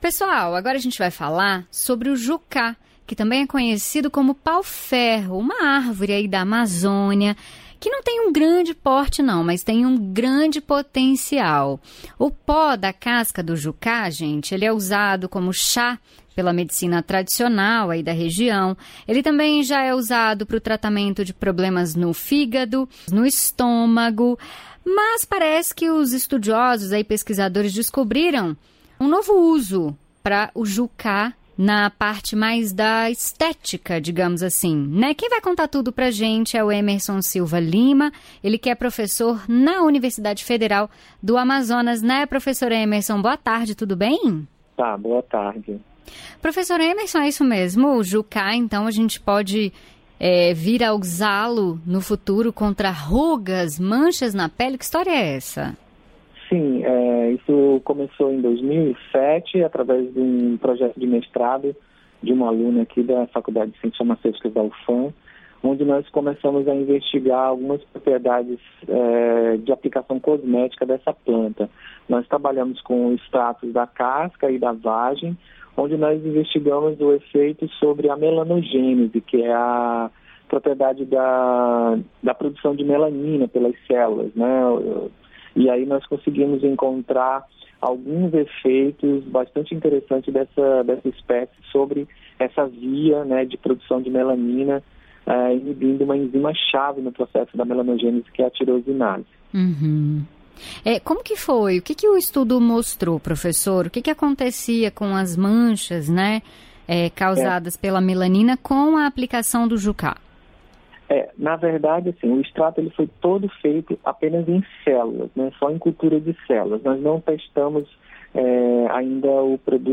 Entrevista: Conheça uma árvore amazônica muito usada na medicina regional